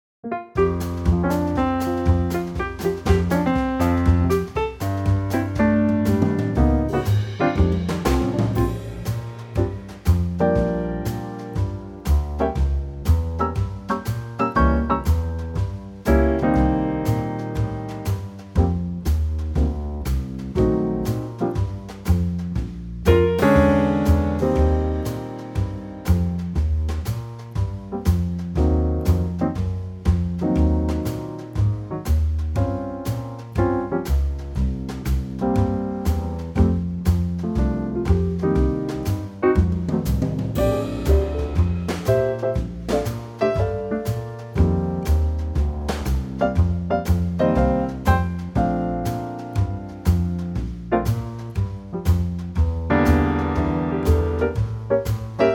short piano solo at 1:29
key - Bb - vocal range - G to G
Nice 2025 Trio arrangement of this popular classic standard.